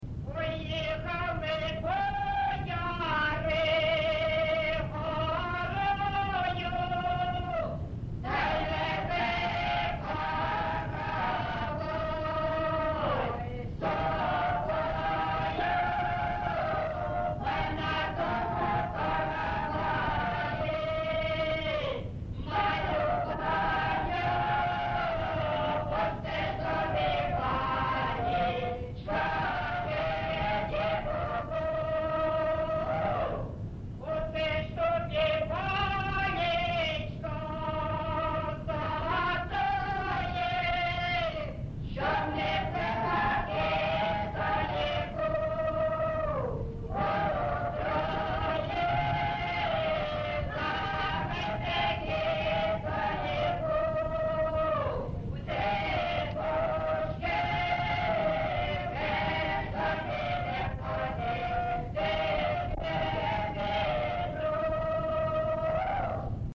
ЖанрВесільні
Місце записус. Семенівка, Краматорський район, Донецька обл., Україна, Слобожанщина